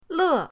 le4.mp3